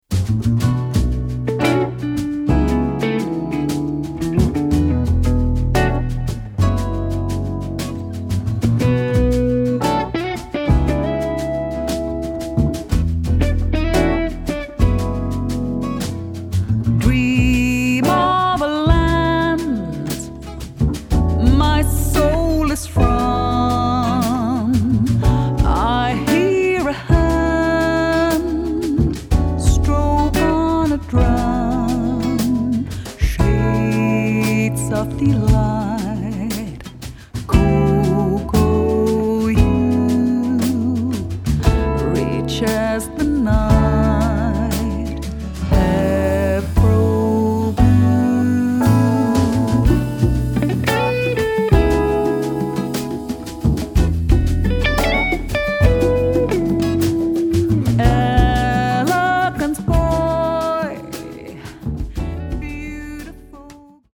jazz klanken
Saxofoon en percussie